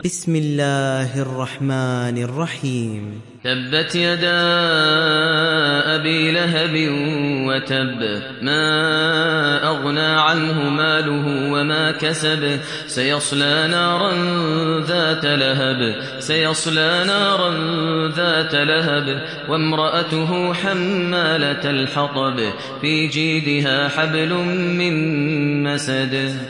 Surah Al Masad Download mp3 Maher Al Muaiqly Riwayat Hafs from Asim, Download Quran and listen mp3 full direct links